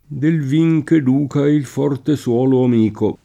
del v&j k ed2ka il f0rte SU0lo am&ko] (Carducci)